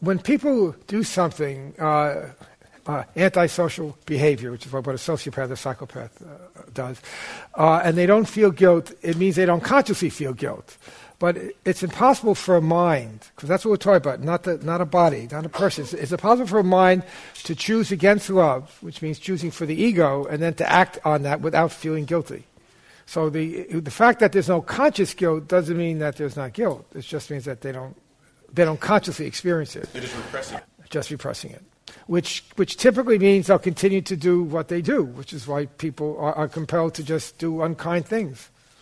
This class highlights the process of forgiveness that enables us to harmonize our words and thoughts, ending the guilt-reinforcing conflict that keeps us imprisoned in the thought system of separation, specialness, and attack.
Original Workshop Date: 11/2010